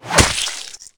monsterclaw.ogg